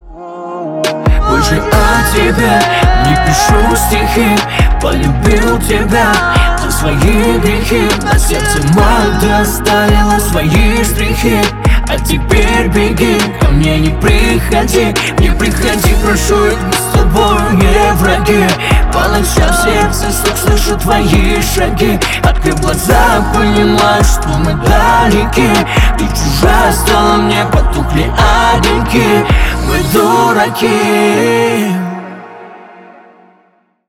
Stereo
Поп Музыка
грустные